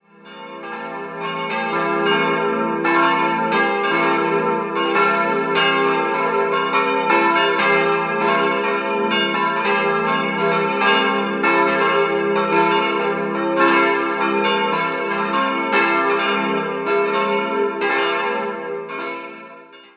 Idealquartett: f'-as'-b'-des'' Die Glocken wurden 1950 von Friedrich Wilhelm Schilling in Heidelberg gegossen.